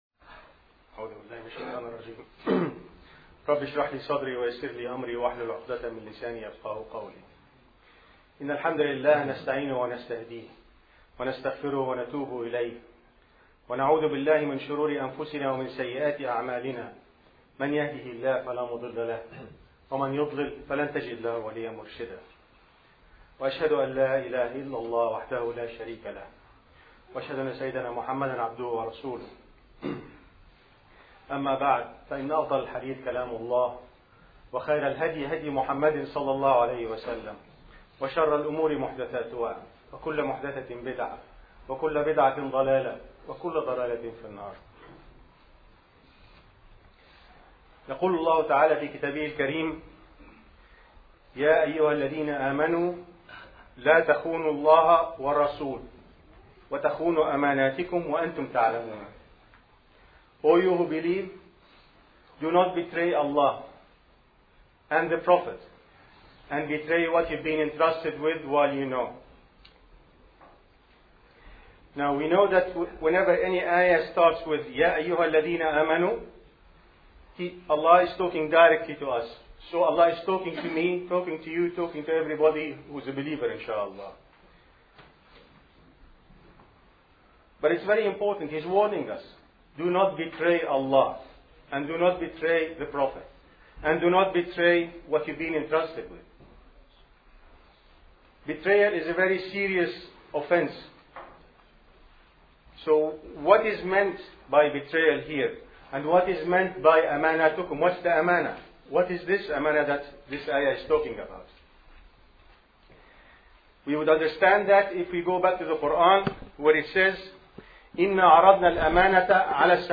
Audio Khutbah